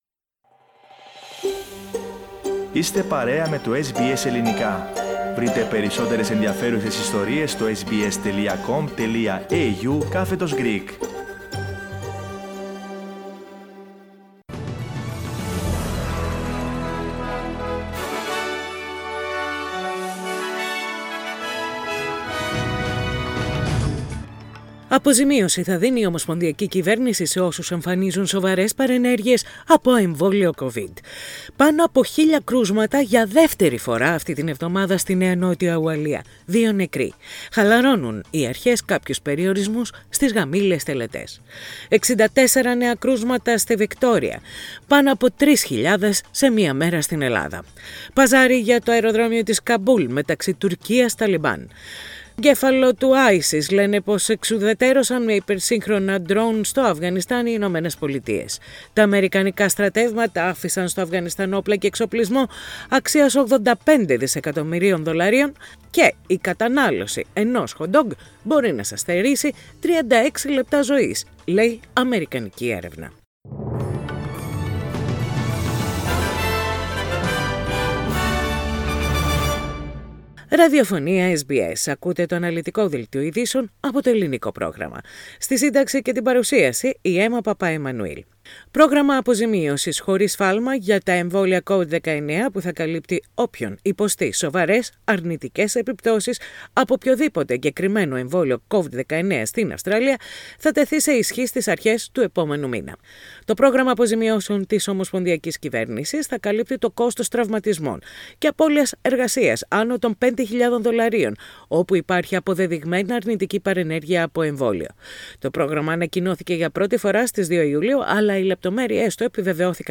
Ειδήσεις στα Ελληνικά - Σάββατο 28.8.21